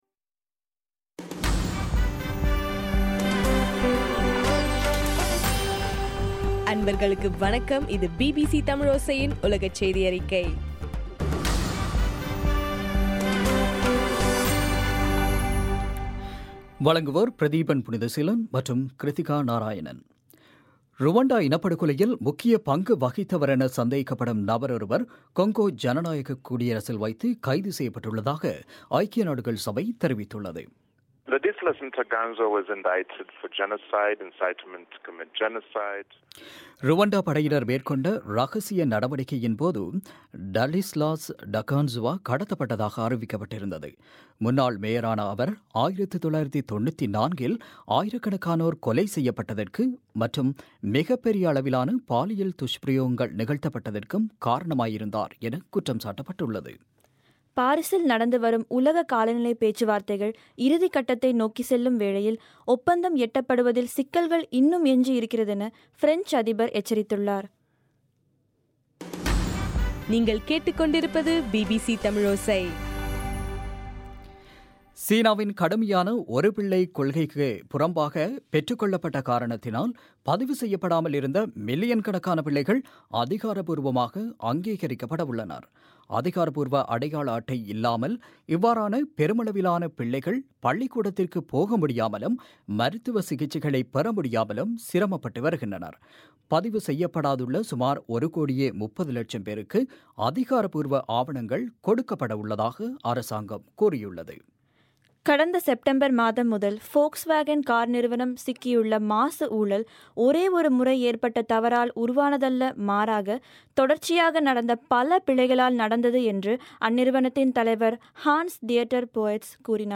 டிசம்பர் 10 பிபிசியின் உலகச் செய்திகள்